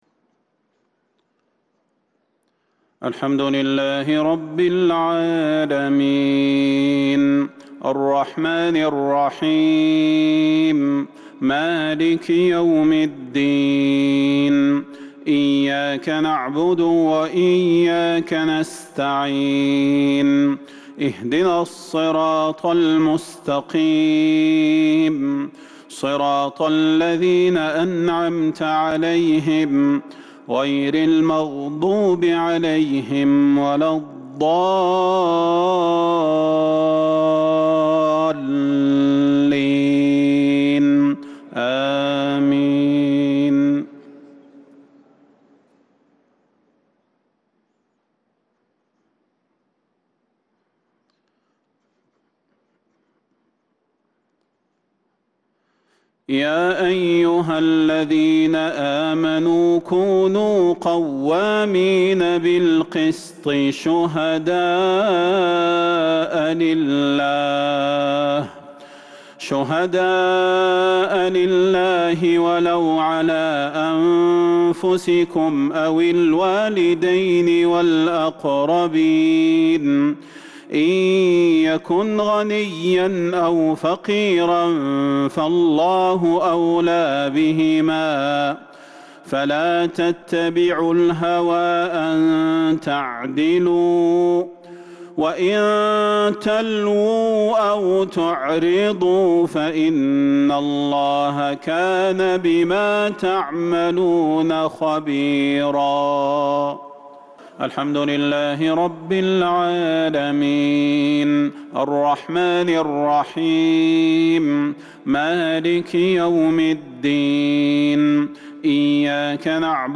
صلاة المغرب للقارئ ياسر الدوسري 24 ربيع الأول 1443 هـ
تِلَاوَات الْحَرَمَيْن .